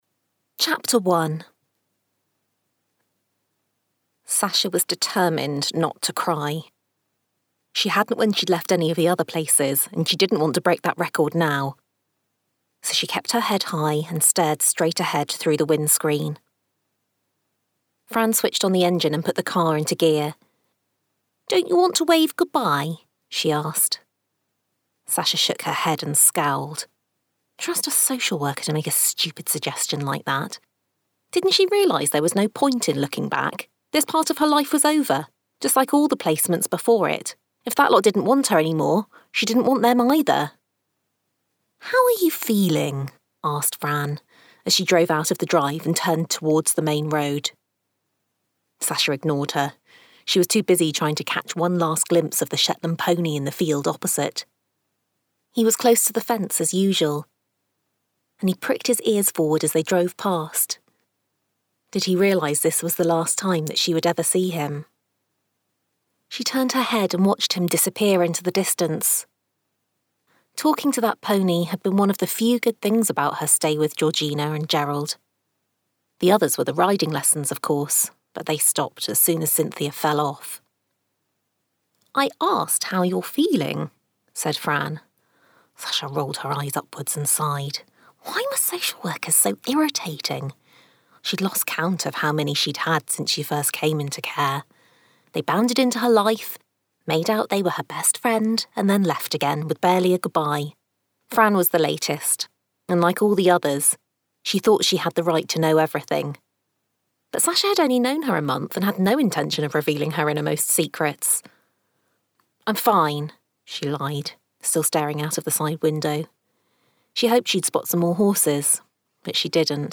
Available as an audiobook, in print and as an ebook.